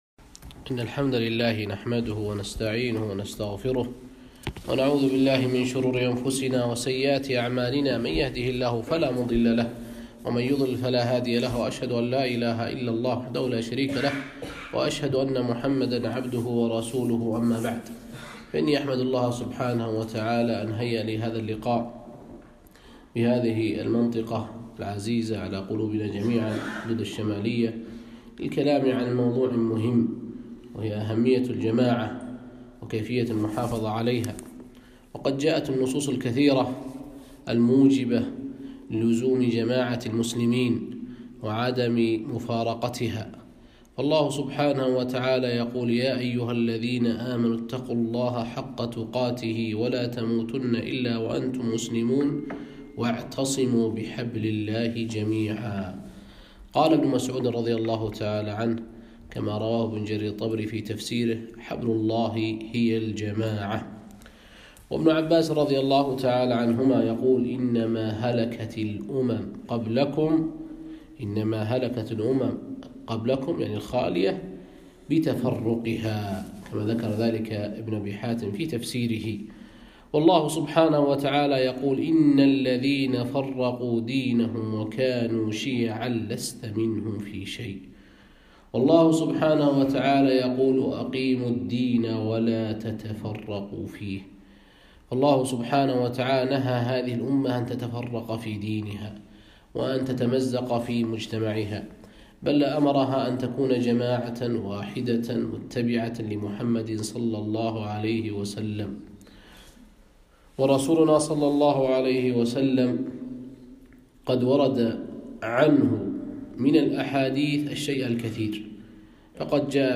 محاضرة - لزوم جماعة المسلمين والمحافظة عليها